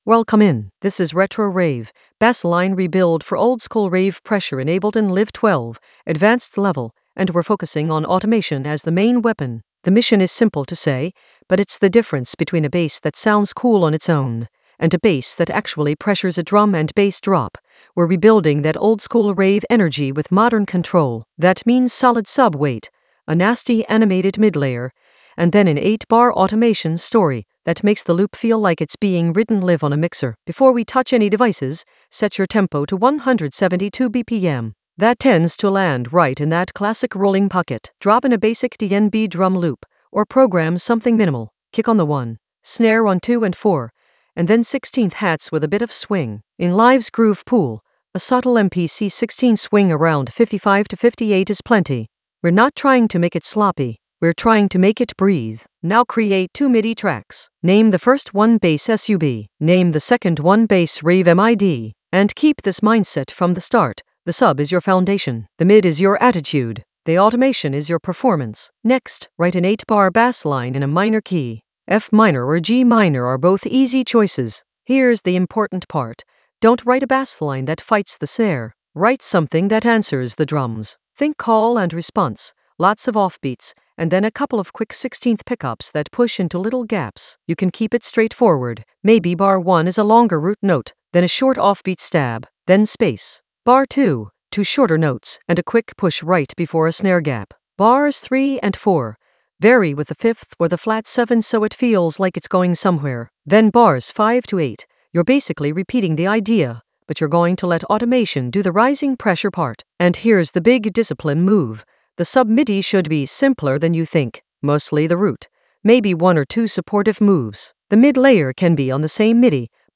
Narrated lesson audio
The voice track includes the tutorial plus extra teacher commentary.
An AI-generated advanced Ableton lesson focused on Retro Rave: bassline rebuild for oldskool rave pressure in Ableton Live 12 in the Automation area of drum and bass production.